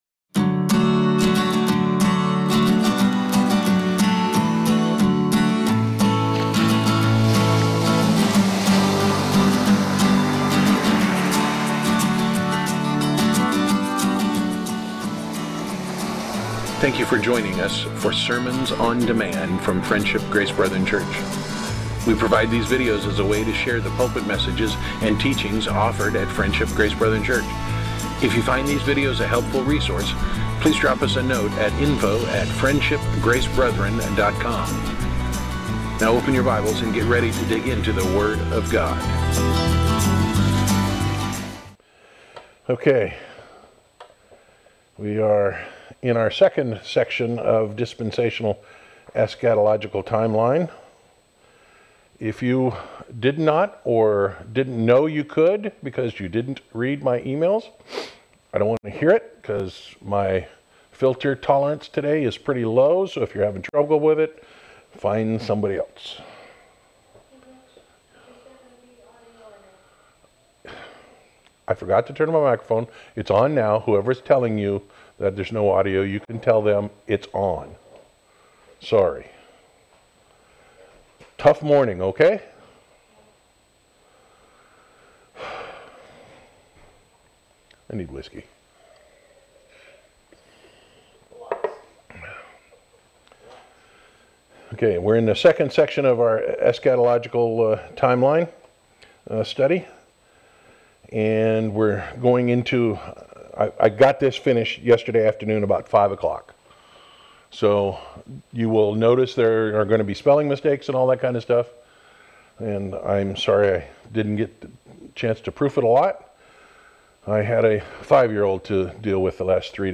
Series: Dispensational Eschatology, Sunday School